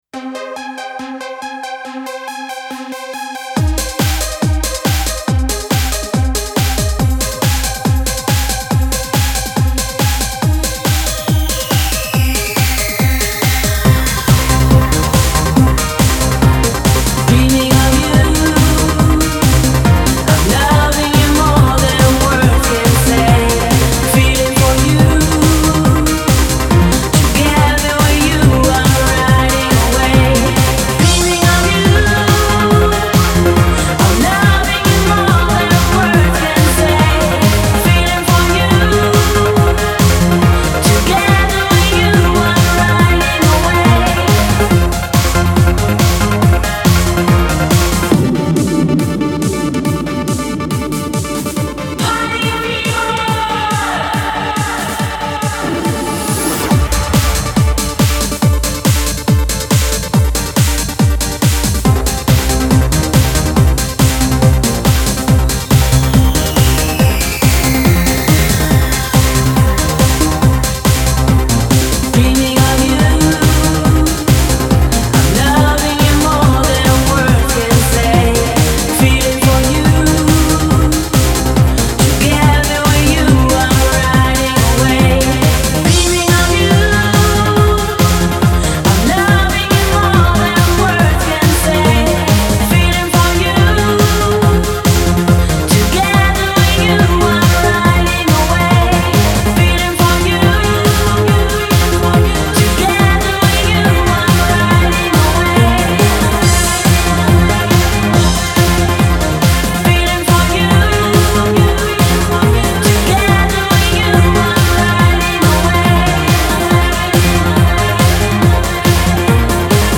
Techno χορευτική διάθεση